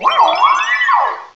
pokeemerald / sound / direct_sound_samples / cries / mesprit.aif
-Replaced the Gen. 1 to 3 cries with BW2 rips.